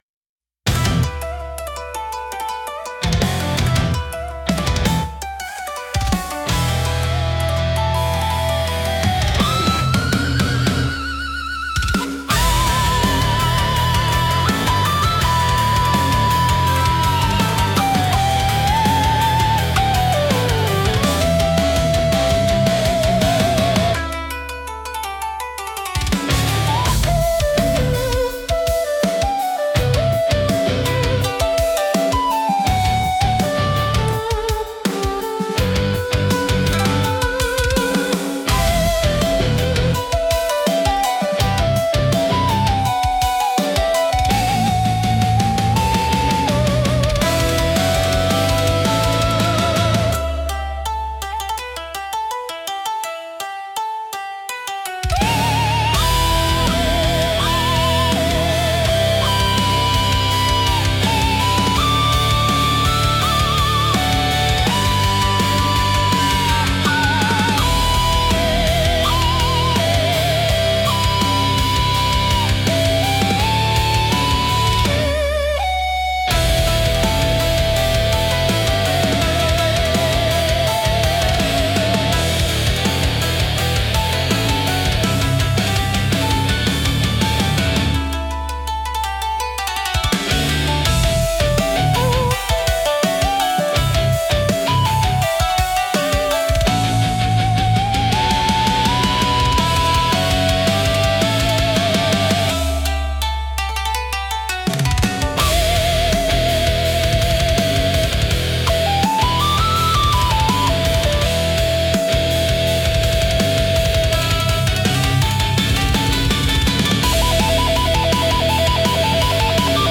聴く人に力強さと神秘性を同時に感じさせ、日本古来の精神と現代のエネルギーを融合したインパクトを与えます。